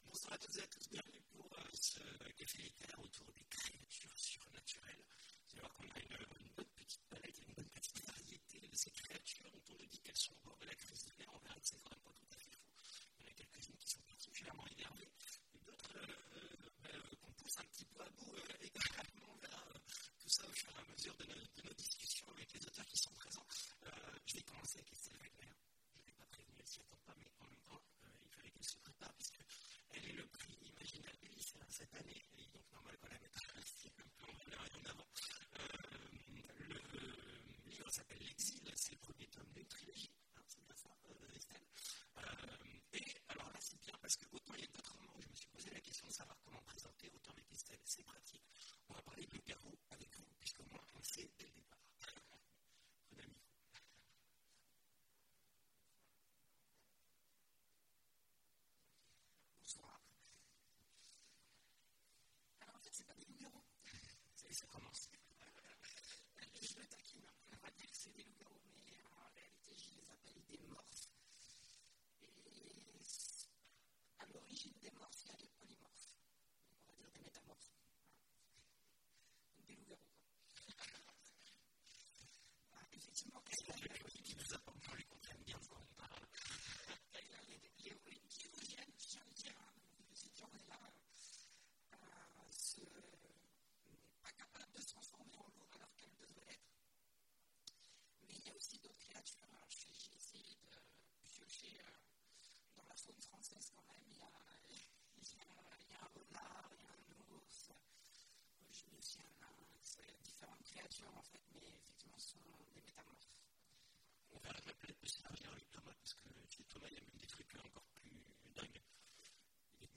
Mots-clés Créatures surnaturelles Conférence Partager cet article